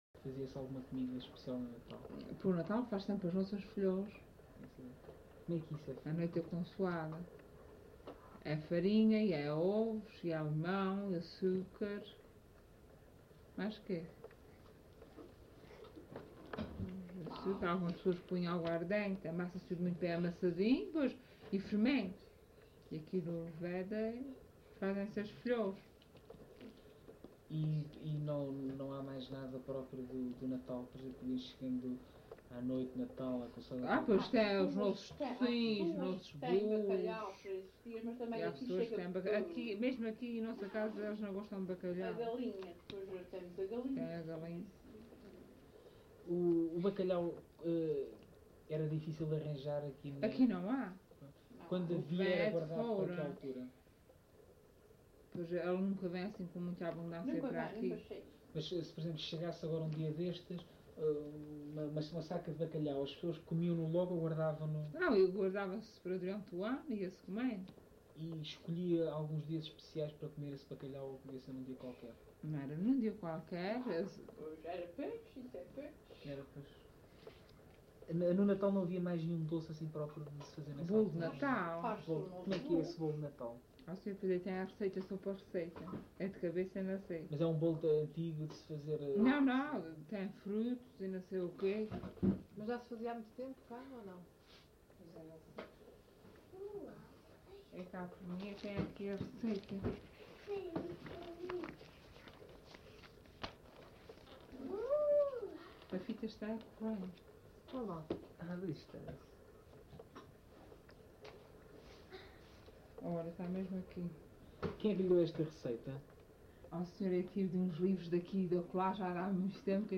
LocalidadeVila do Corvo (Corvo, Horta)